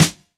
Present Snare One Shot E Key 40.wav
Royality free snare tuned to the E note. Loudest frequency: 2549Hz
present-snare-one-shot-e-key-40-F3w.ogg